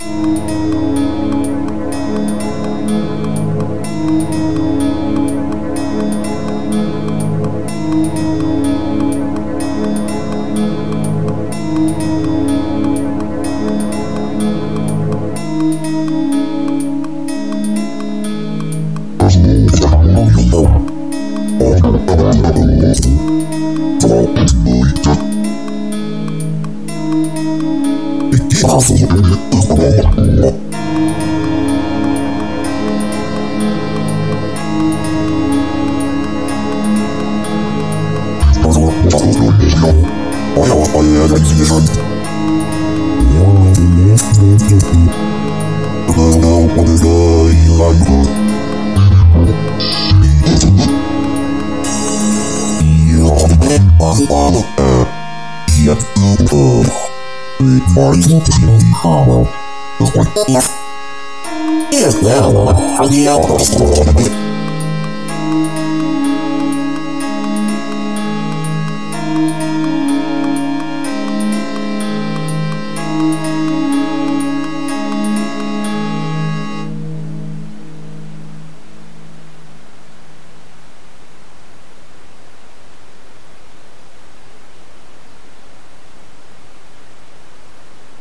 vaporwave, chiptune, videogame music, vgm, midiwave,